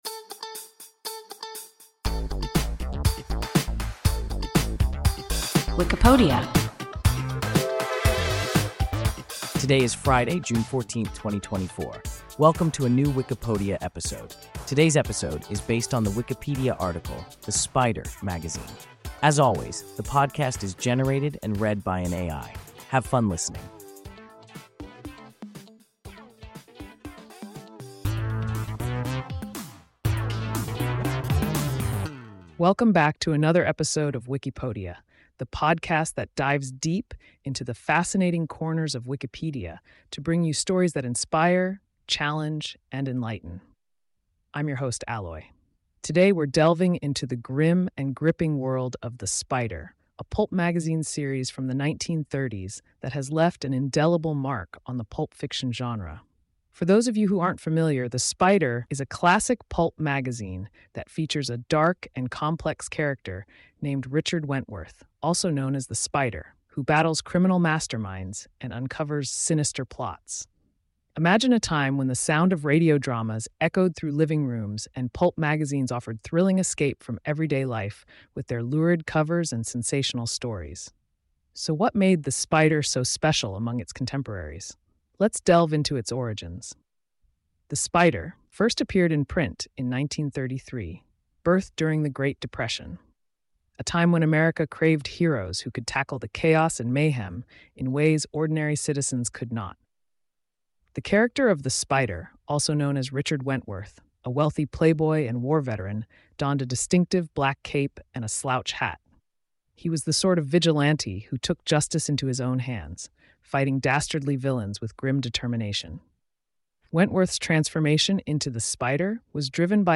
The Spider (magazine) – WIKIPODIA – ein KI Podcast